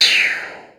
RetroGamesSoundFX
Shoot10.wav